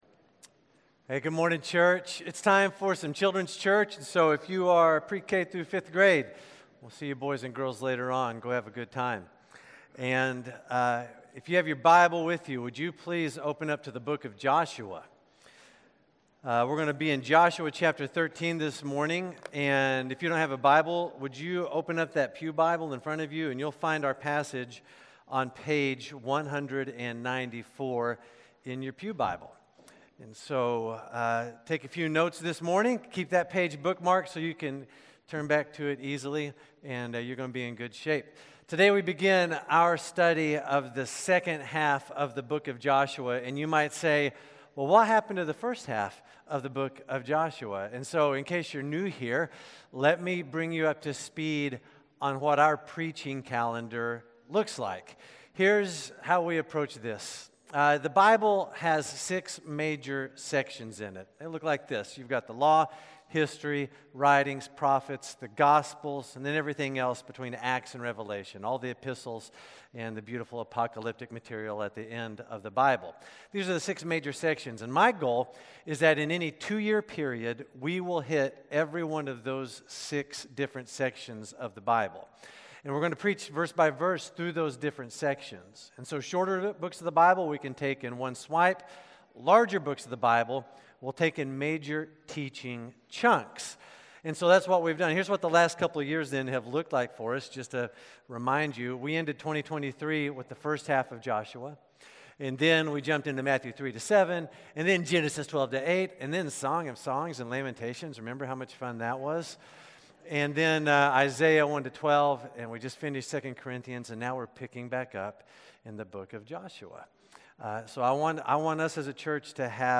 South Shore Baptist Church Sermons Podcast - Peace in the Undone - Joshua 13 | Free Listening on Podbean App